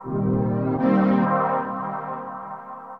SYNTHPAD004_AMBNT_160_C_SC3(L).wav